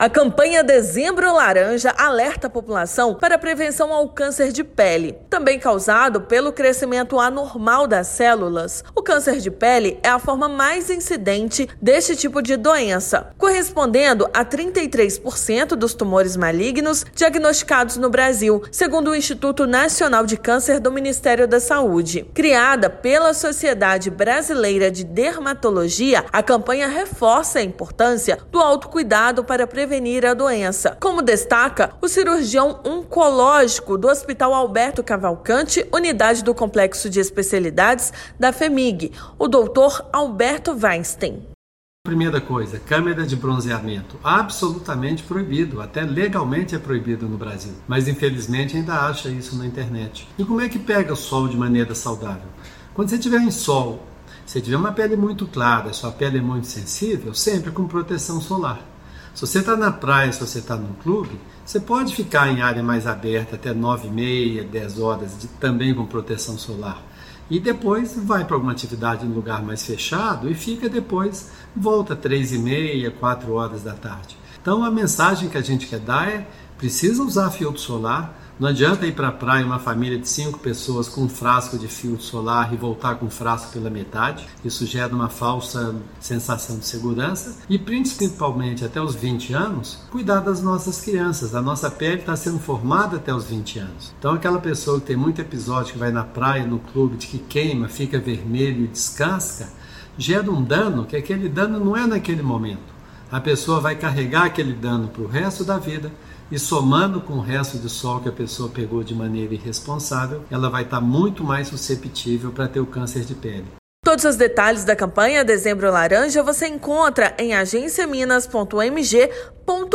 Campanha reforça cuidados contra um dos tipos mais comuns da doença. Ouça a matéria de rádio.